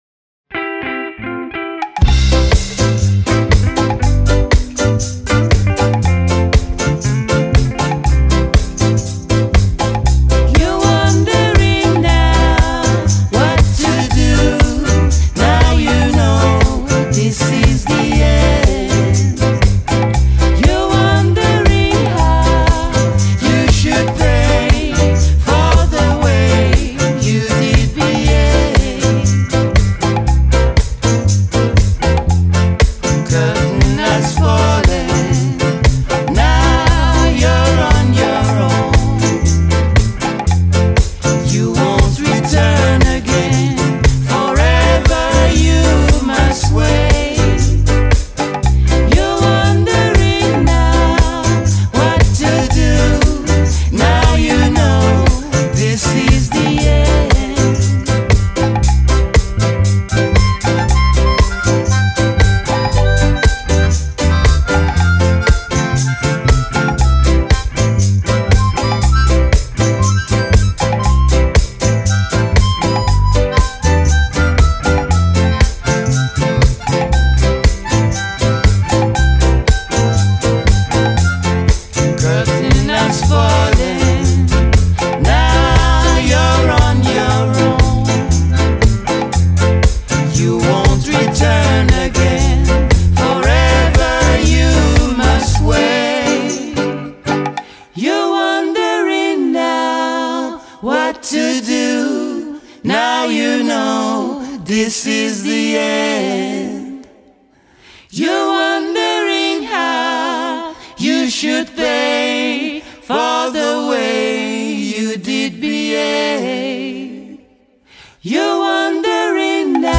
Artista-a-la-Vista-Intervista-Earl-Chinna-Smith-Rototom-2024.mp3